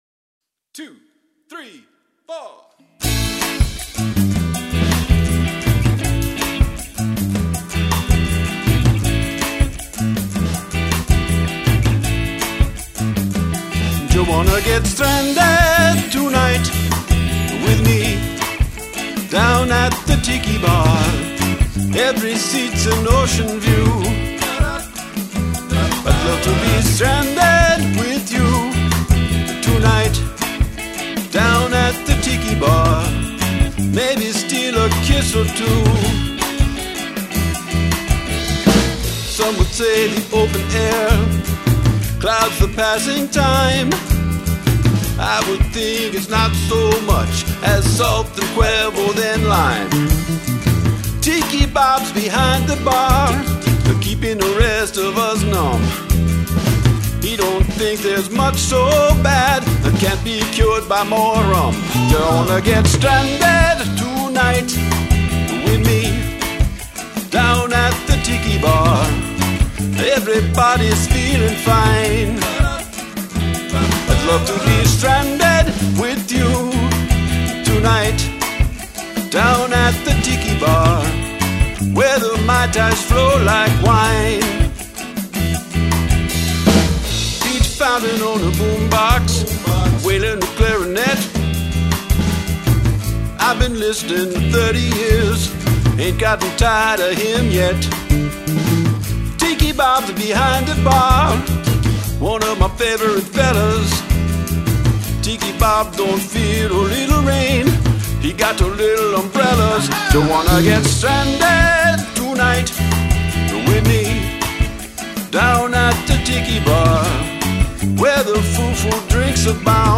Song must include whispering